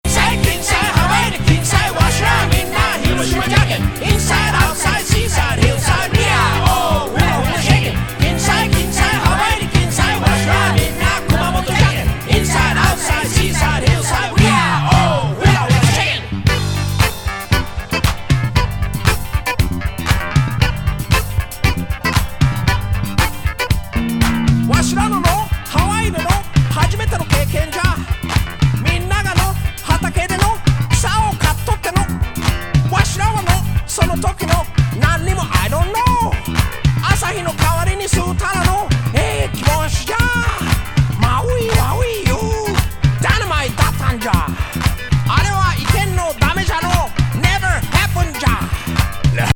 和ディスコ＋オールドスクール下世話ラッピン・クラシック!